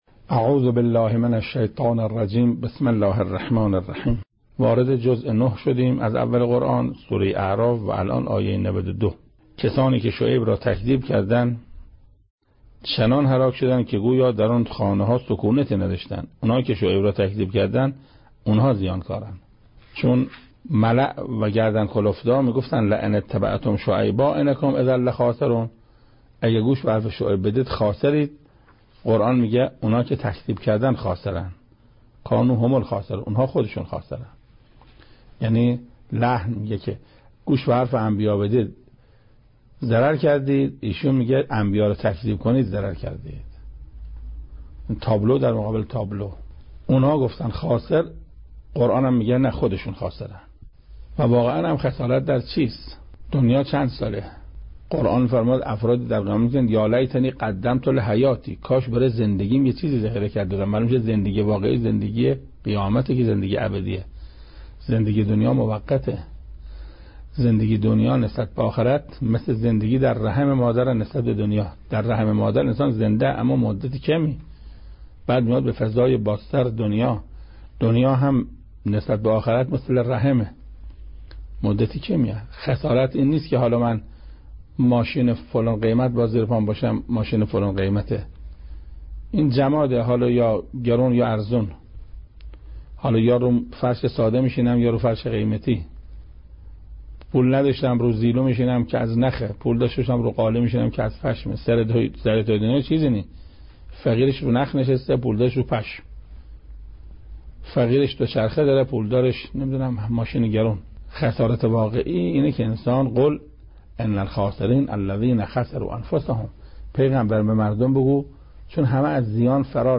تفسیر سوره(استاد قرائتی) بخش اول